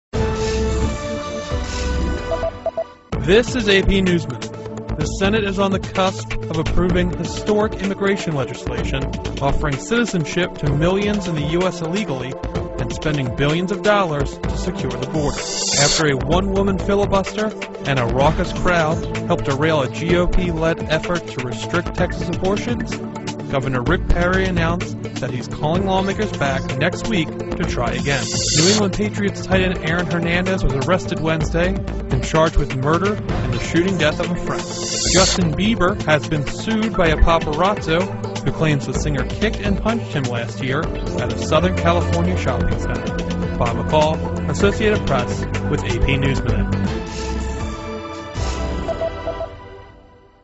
在线英语听力室美联社新闻一分钟 AP 2013-07-02的听力文件下载,美联社新闻一分钟2013,英语听力,英语新闻,英语MP3 由美联社编辑的一分钟国际电视新闻，报道每天发生的重大国际事件。电视新闻片长一分钟，一般包括五个小段，简明扼要，语言规范，便于大家快速了解世界大事。